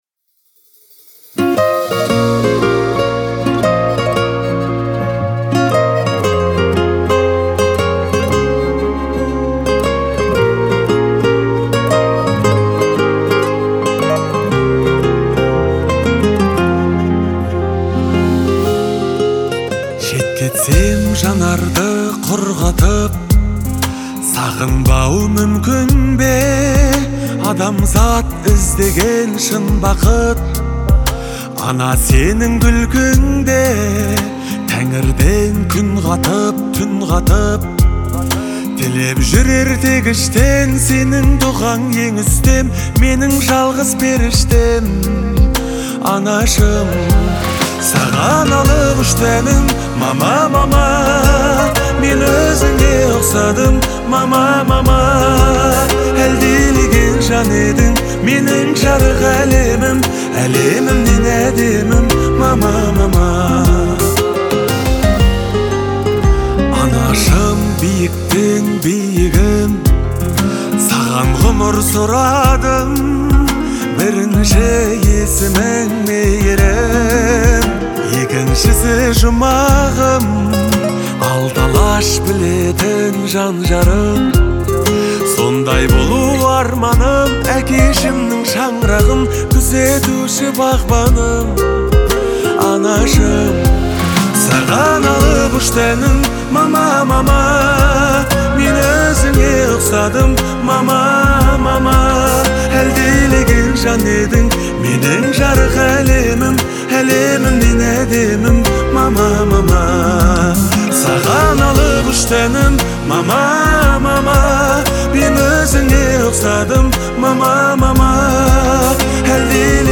• Категория: Казахские песни /